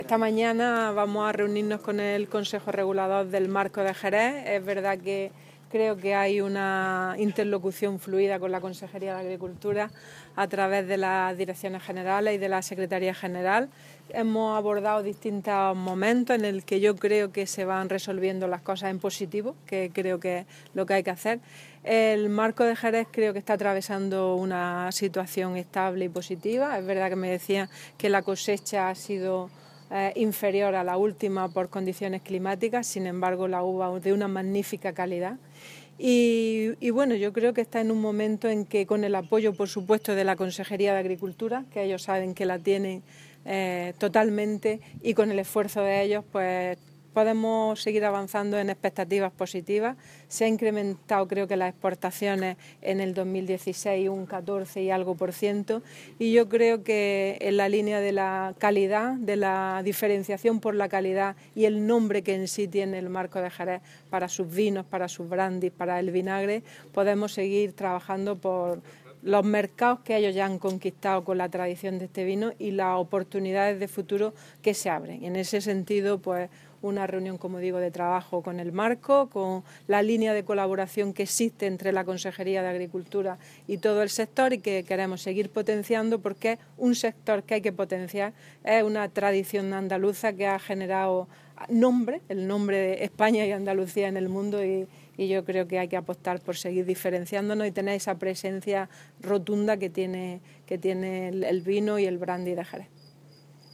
Declaraciones Carmen Ortiz sobre Marco de Jerez